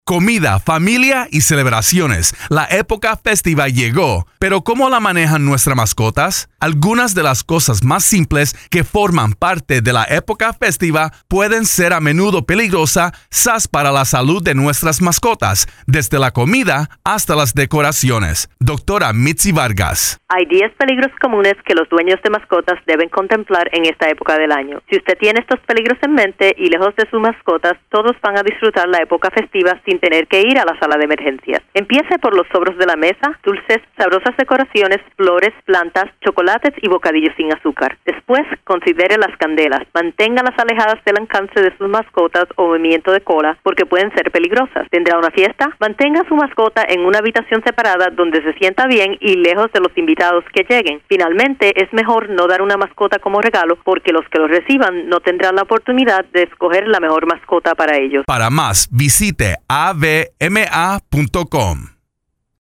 November 20, 2012Posted in: Audio News Release